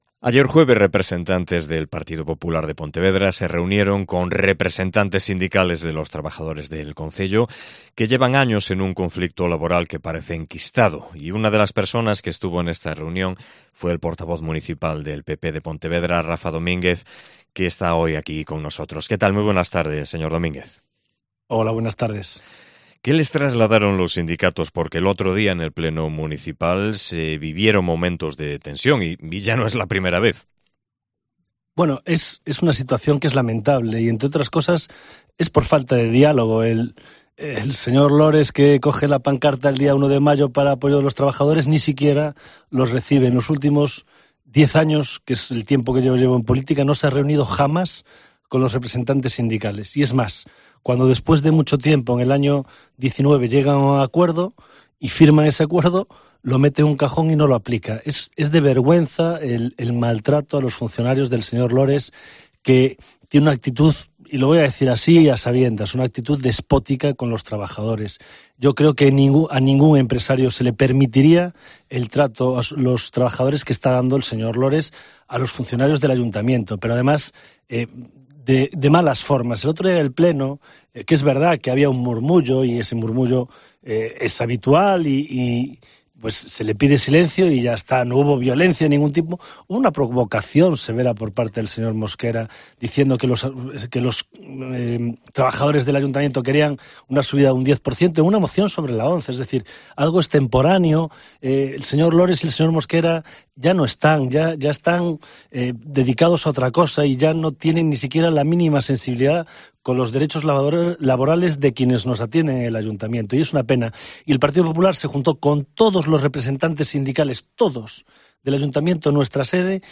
AUDIO: Entrevista patrocinada por el Grupo Municipal del Partido Popular